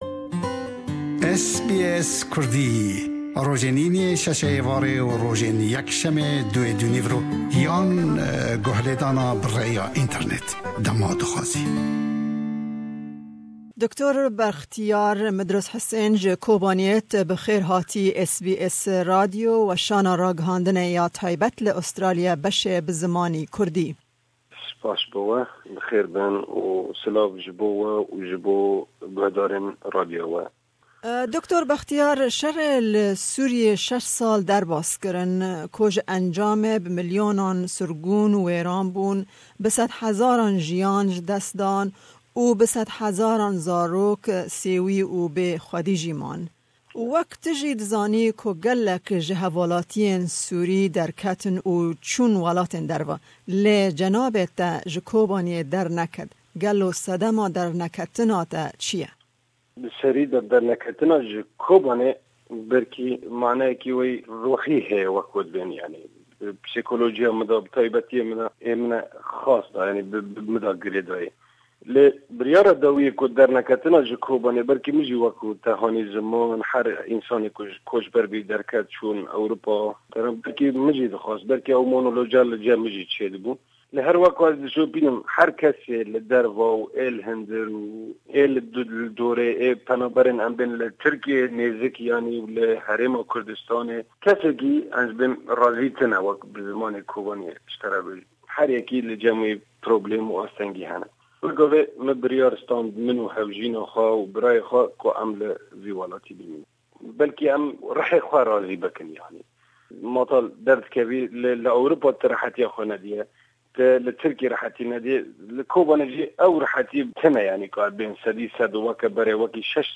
hevpeyvînek ji Kobaniyê bi rê xist.